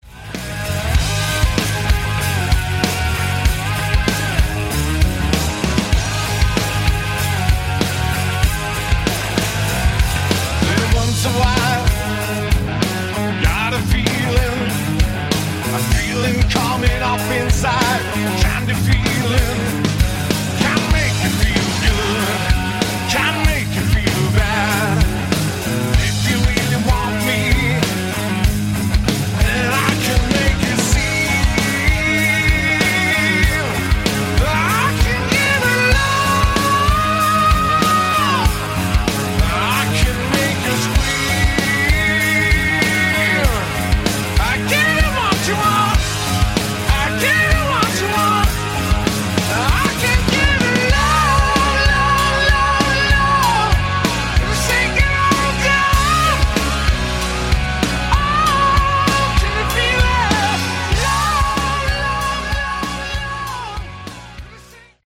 Category: Hard Rock
lead vocals
guitar, vocals
backing vocals